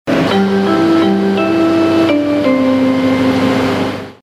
Spoznaj zvučku železničnej stanice a vyhraj kozmetický balíček.
Na ktorej železničnej stanici môžeš počuť toto?